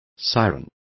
Complete with pronunciation of the translation of siren.